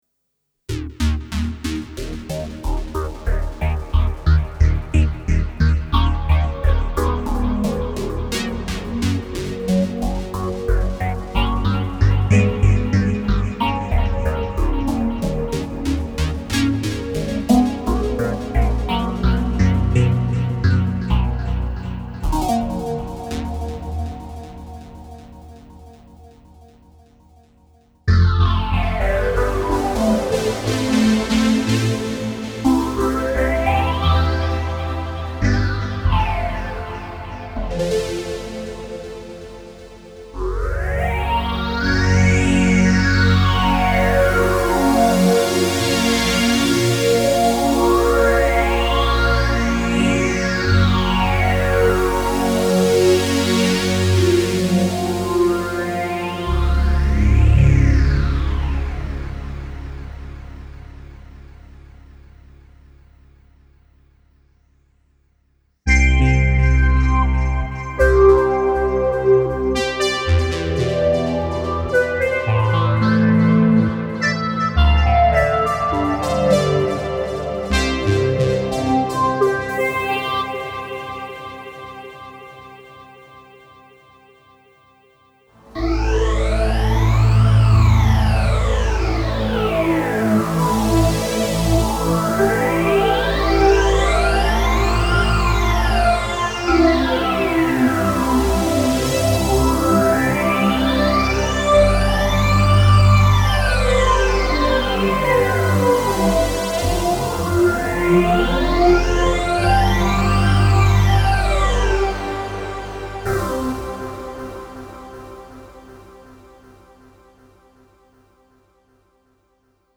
Блин, красиво звучит. Примеры 6-stage c резонансом на максимуме, в конце 12-stage с таким же резонансом